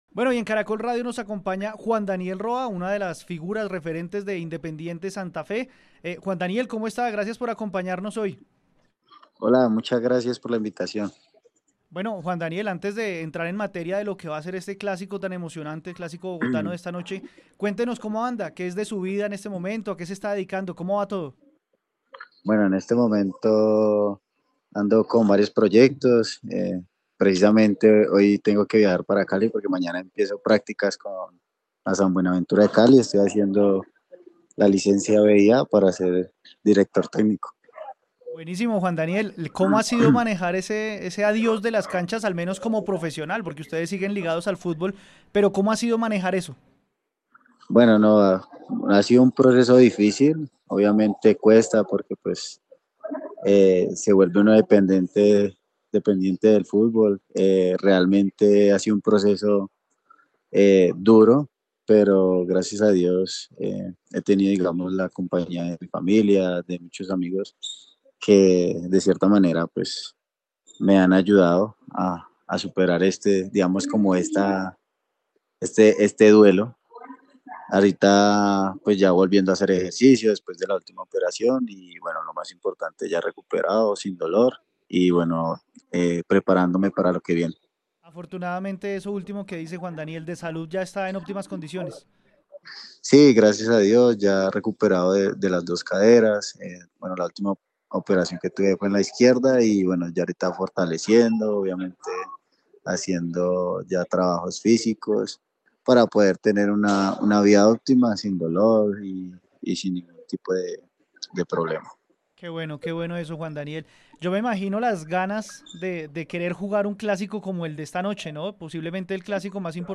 En diálogo con Caracol Radio, Juan Daniel Roa dio a conocer sus impresiones para este compromiso.